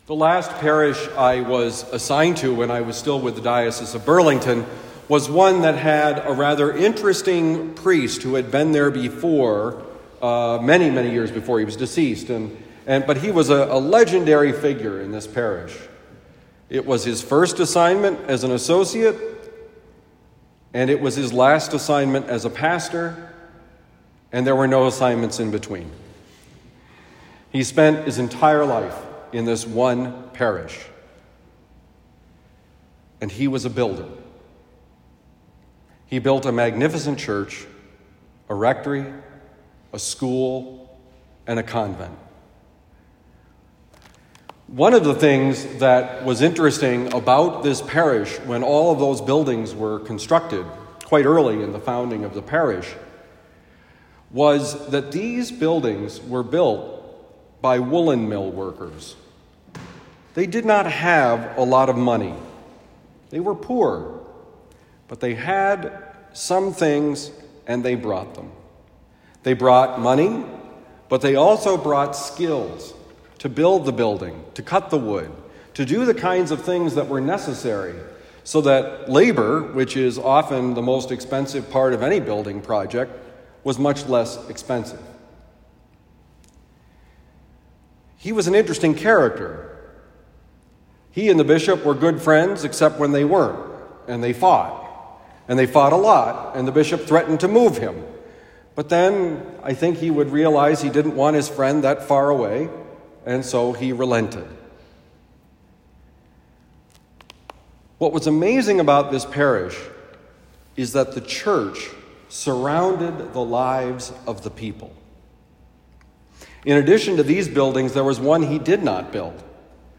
Homily for the Anniversary of the Dedication of Saint Dominic Priory Chapel
Given at St. Dominic Priory, Saint Louis, Missouri.